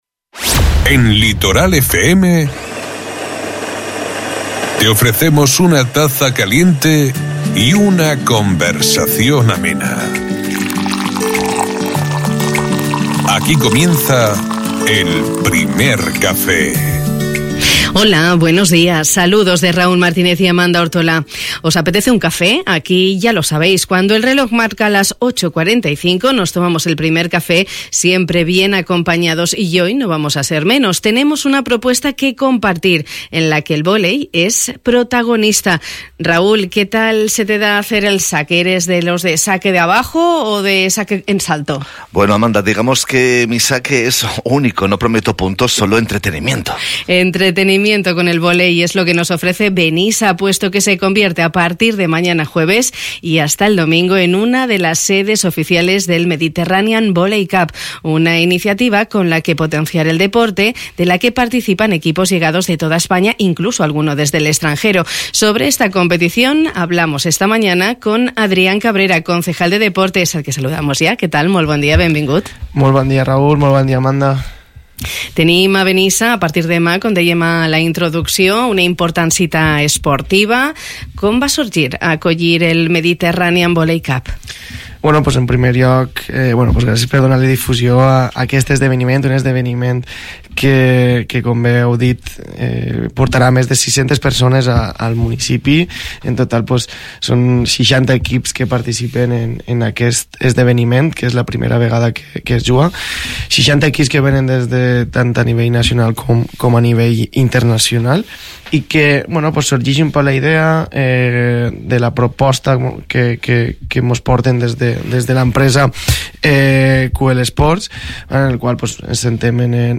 Una iniciativa con la que potenciar el deporte, sobre la que hemos hablado esta mañana en el Primer Café con Adrián Cabrera, concejal de Deportes en el Ayuntamiento de Benissa.